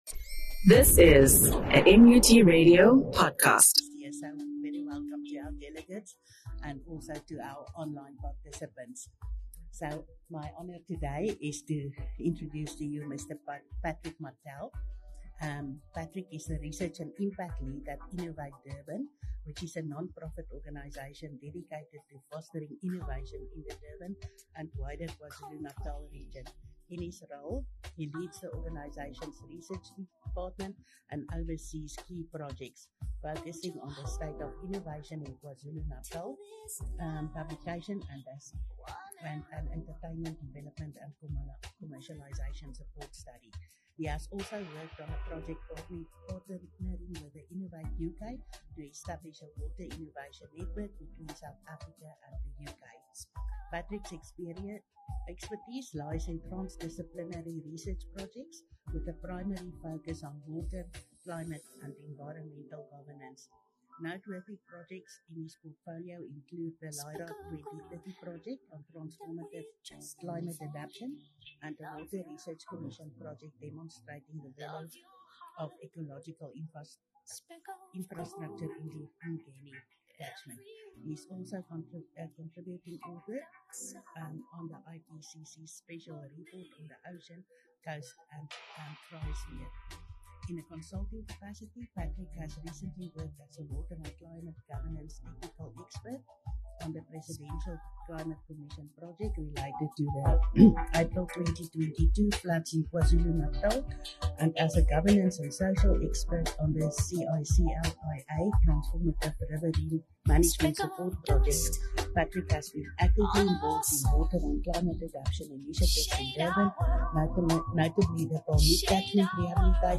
The lecture inspired critical thinking on leveraging IP to achieve SDG targets effectively.